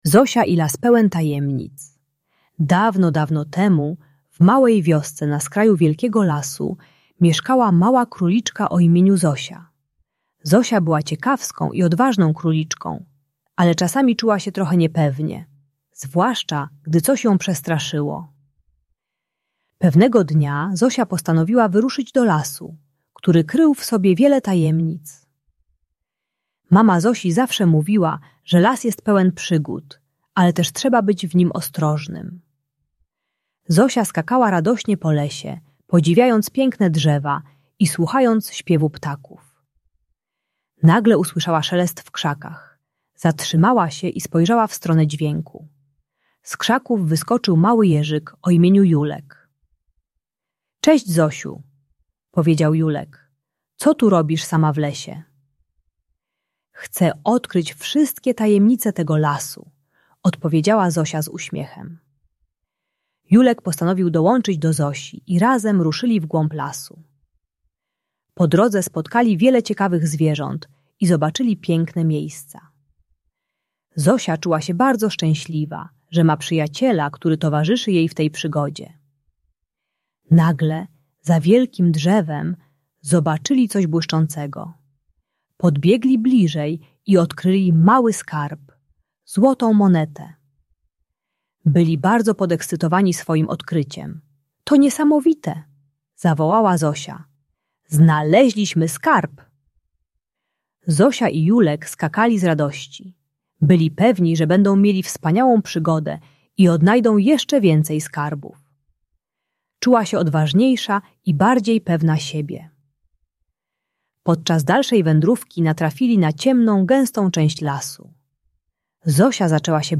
Historia króliczki Zosi uczy, że warto mówić o swoich uczuciach zamiast reagować buntem. Audiobajka o przezwyciężaniu strachu poprzez komunikację i szukanie wsparcia u bliskich.